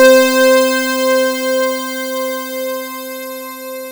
KORG C5  1.wav